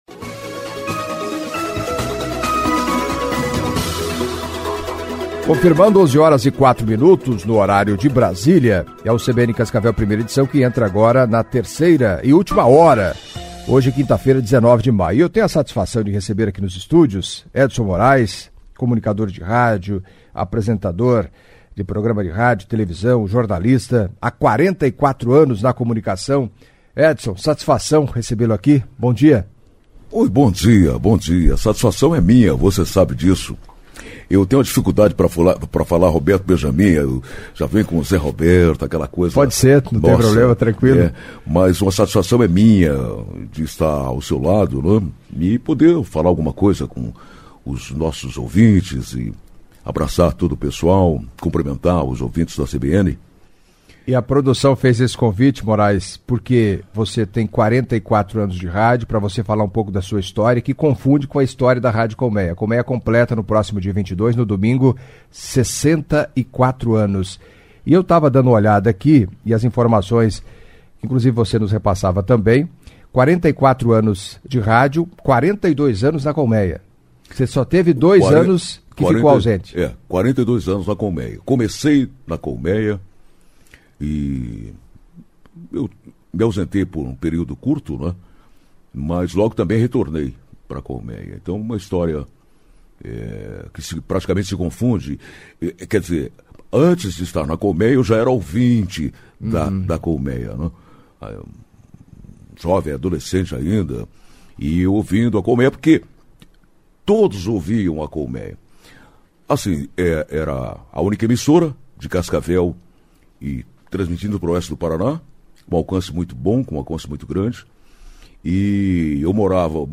Em entrevista à CBN Cascavel nesta quinta-feira